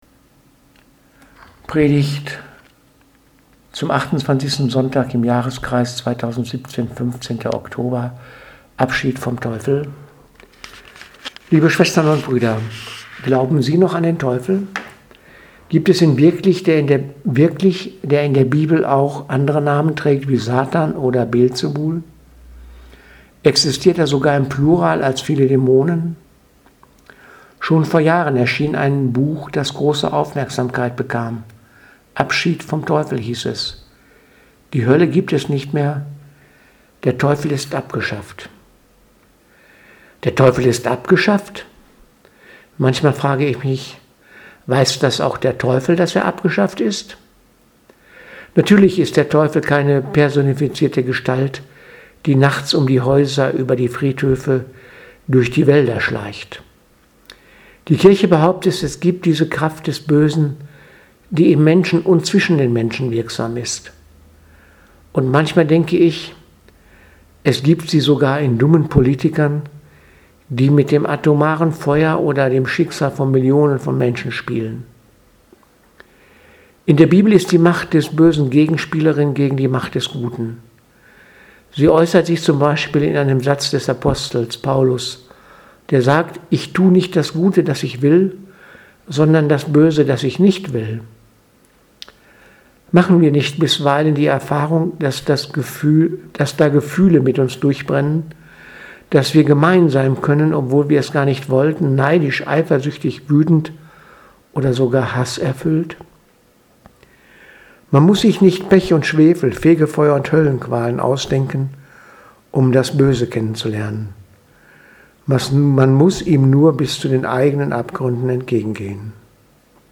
Predigt vom 15.10.2017 – Teufel